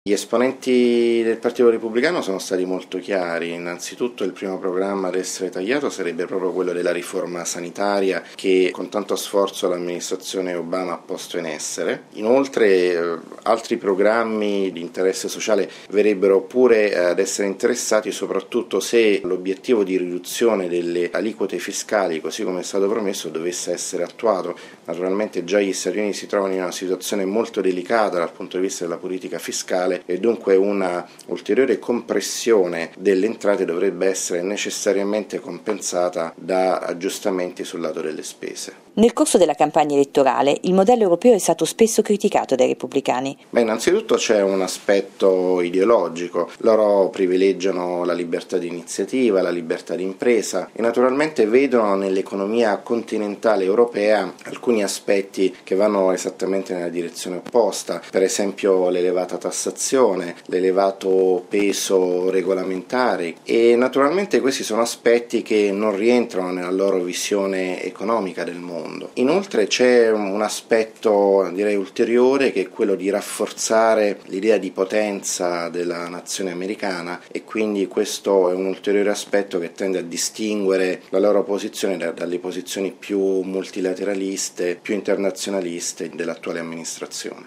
Continuano negli Stati Uniti le primarie del Partito Repubblicano.